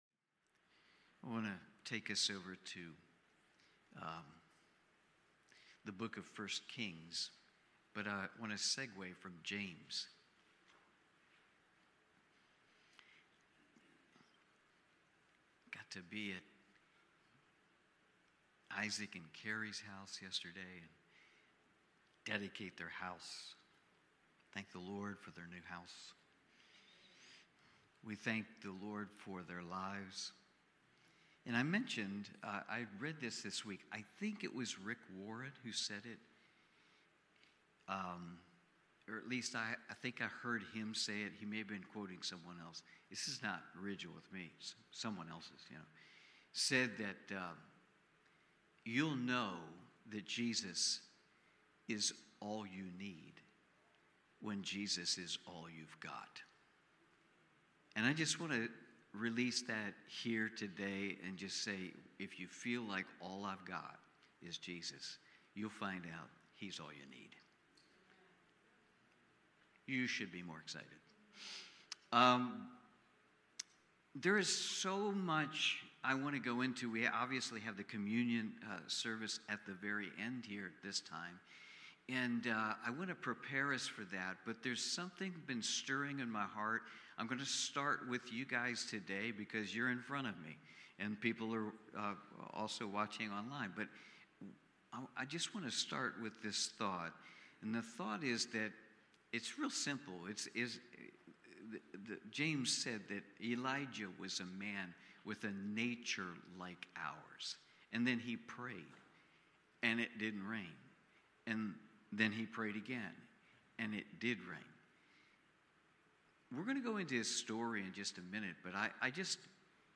I Kings Watch Listen Save Cornerstone Fellowship Sunday morning service, livestreamed from Wormleysburg, PA.